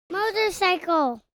motorcyclewtwav.mp3